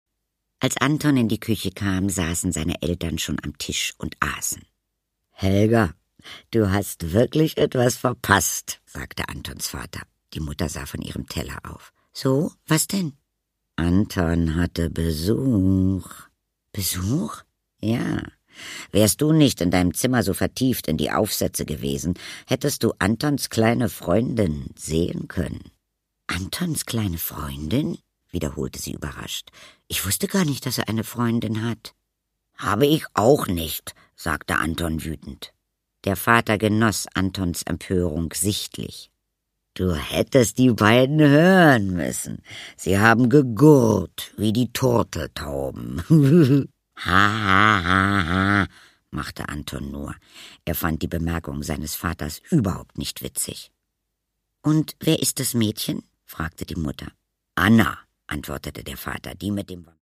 Angela Sommer-Bodenburg: Der kleine Vampir und die große Liebe - Der kleine Vampir, Band 5 (Ungekürzte Lesung mit Musik)
Produkttyp: Hörbuch-Download
Gelesen von: Katharina Thalbach